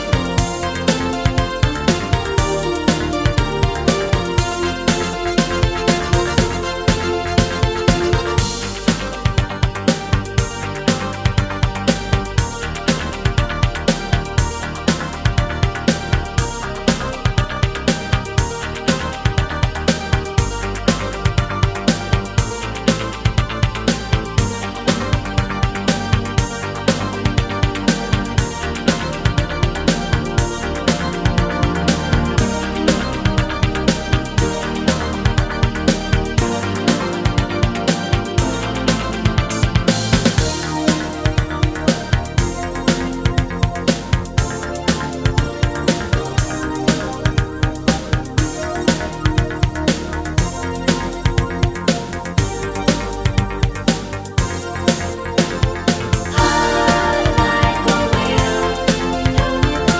for duet Pop (1980s) 4:19 Buy £1.50